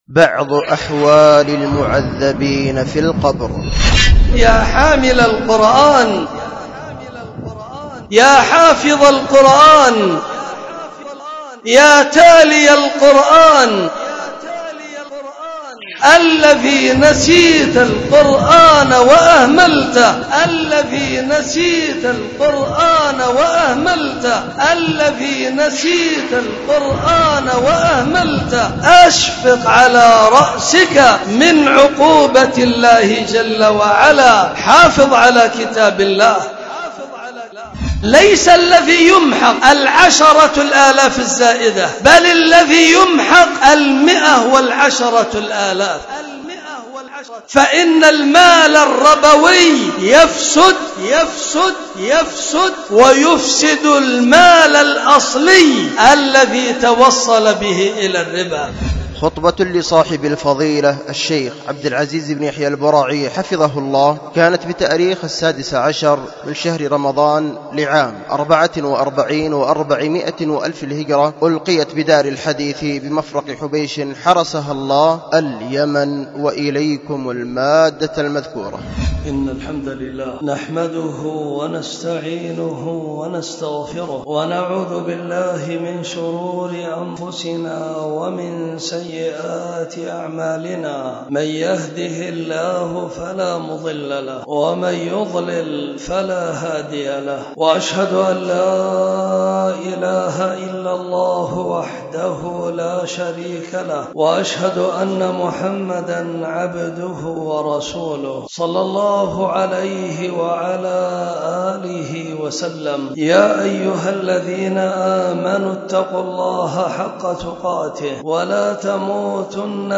ألقيت بدار الحديث بمفرق حبيش